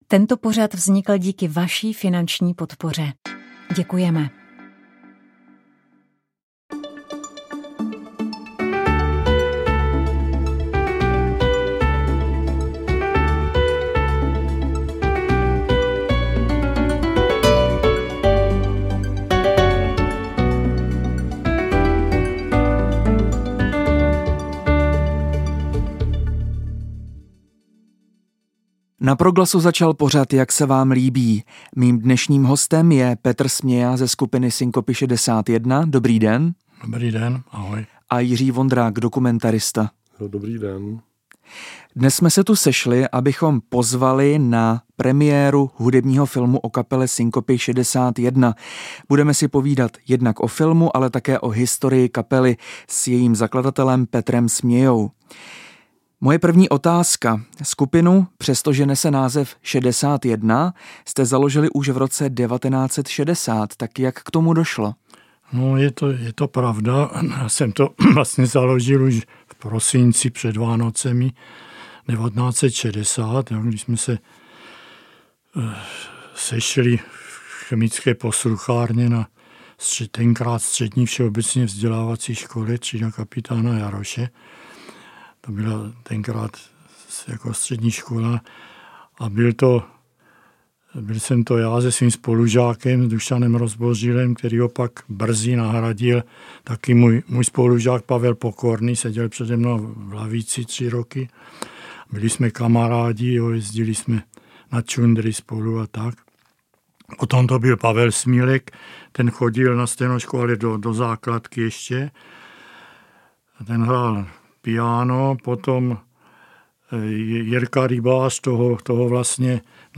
Rozhovor s íránskou skupinou Rastak Music